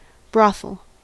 Ääntäminen
US : IPA : [bɹɒ.θəl]